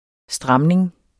Udtale [ ˈsdʁɑmneŋ ]